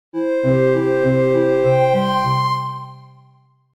SE（勝利）
バトル 演出 SE ファンファーレ 勝利 スポンサーリンク シェアする Twitter Facebook LINE コピー Pure Score Pure Score 関連記事 機械 SE（タップ） タップ音。